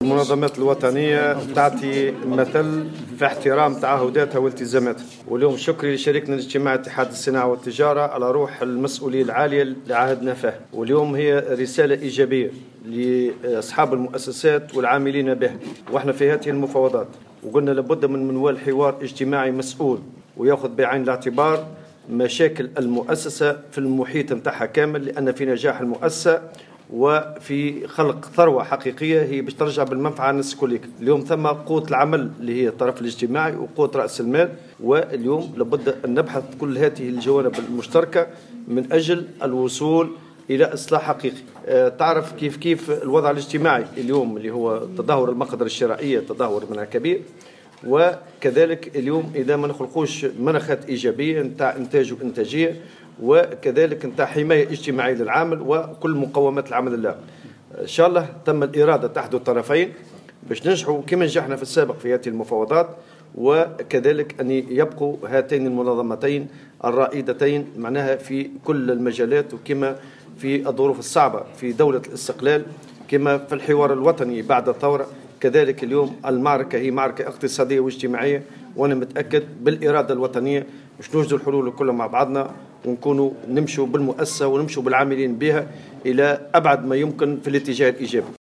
قال الأمين العام للاتحاد العام التونسي للشغل نور الدين الطبوبي، في تصريح لمراسل الجوهرة اف ام اثر انتهاء أول جلسة حول المفاوضات الاجتماعية في القطاع الخاص، إن هناك إرادة من جانب اتحاد الشغل واتحاد الصناعة والتجارة والصناعات التقليدية لإنجاح هذه المفاوضات التي ستشمل الجانب الترتيبي والمالي.